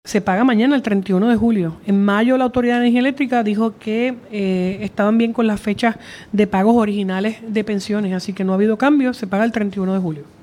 De igual forma, la gobernadora Jenniffer González Colón, afirmó en conferencia de prensa que, en efecto, las pensiones se pagarán este jueves.
328-JENNIFFER-GONZALEZ-GOBERNADORA-LAS-PENSIONES-DE-LOS-JUBILADOS-DE-LA-AEE-SE-PAGAN-MANANA.mp3